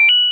alert.coin.mp3